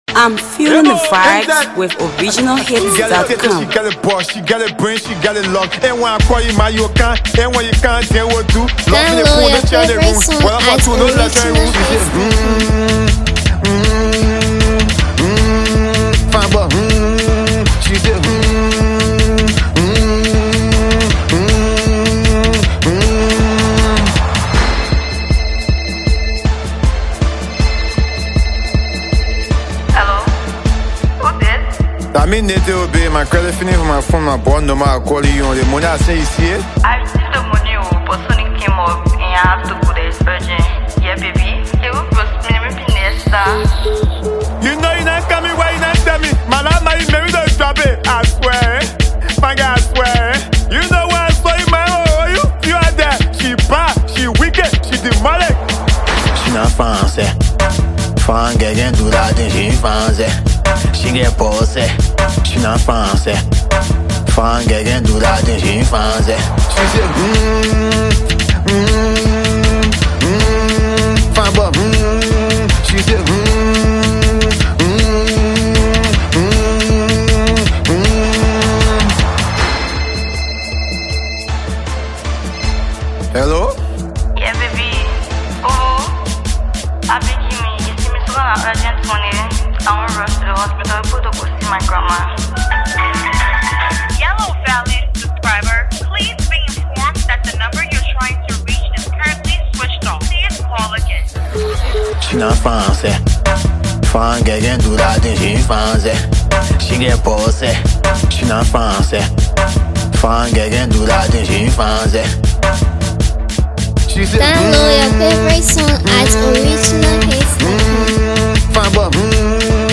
With its infectious rhythm and groovy vibe
stands out as a danceable masterpiece